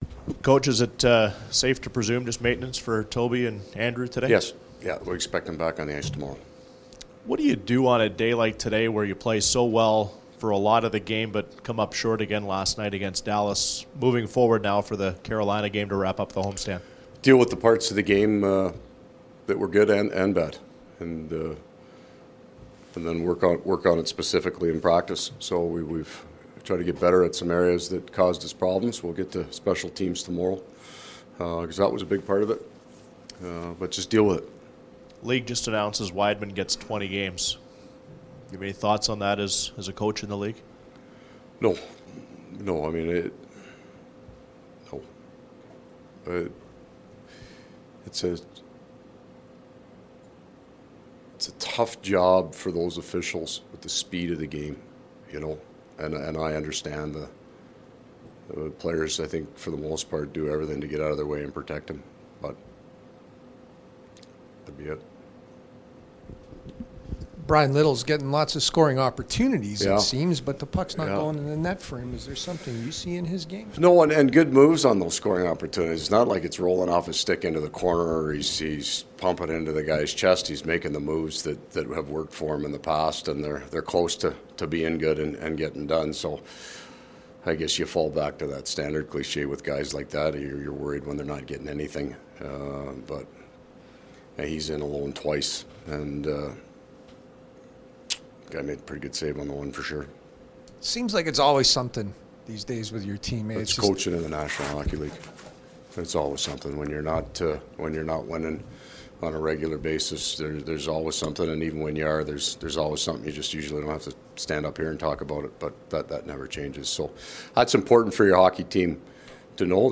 Coach scrum
Coach Maurice chatted with media following the skate at MTS Centre.